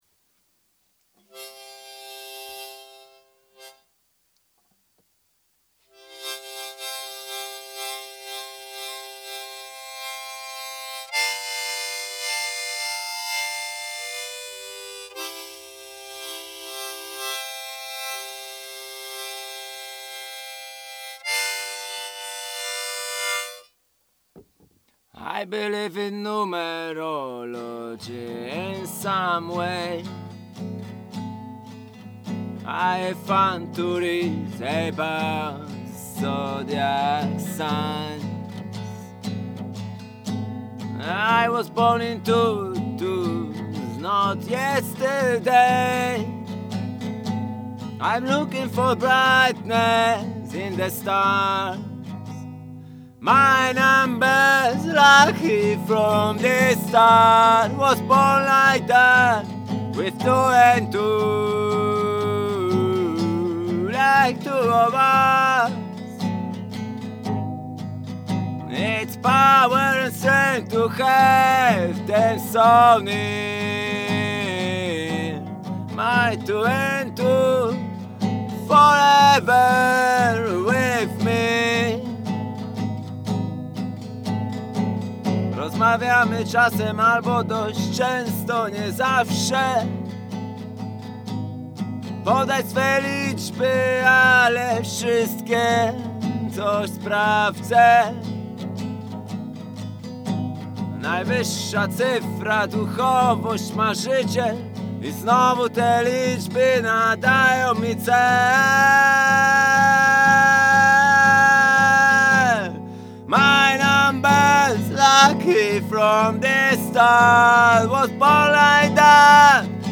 artist & one-person music band